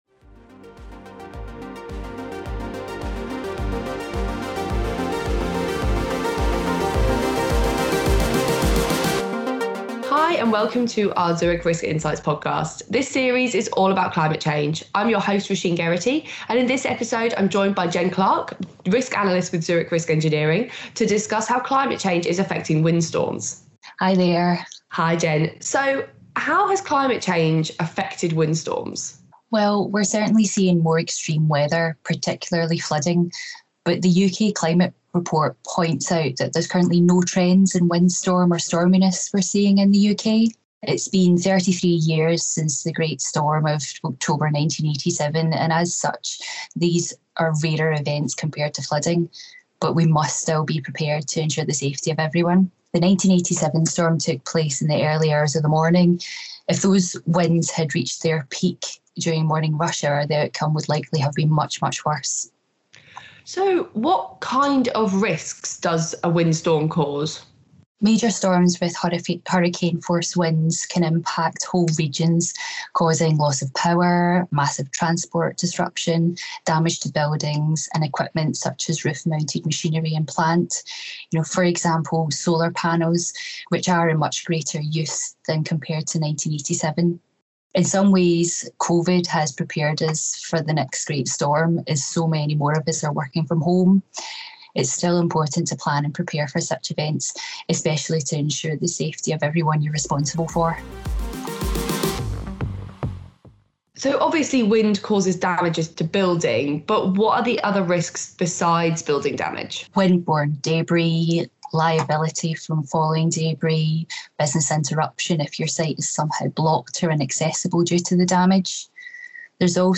Our new brand purpose is to create a brighter future for all, so in this next series of podcasts we interview experts from across the Zurich business and explore how climate change is impacting risk management practices and how organisations can prepare for these fast changing risks.